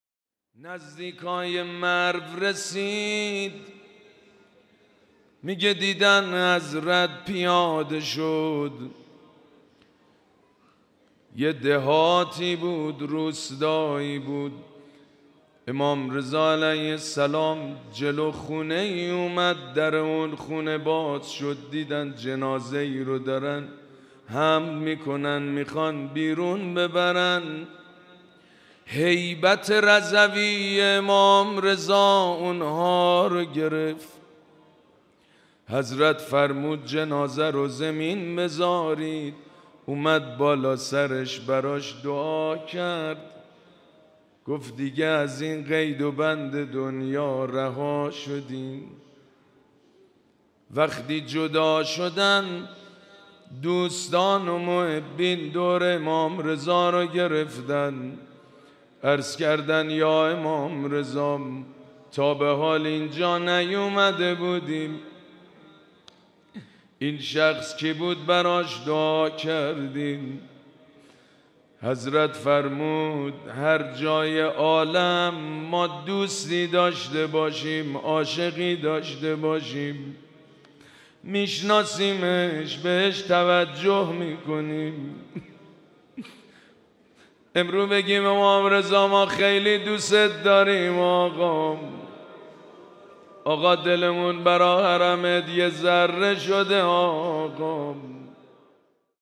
مراسم عزاداری ظهر سی‌ام ماه صفر
حسینیه امام خمینی (ره)
روضه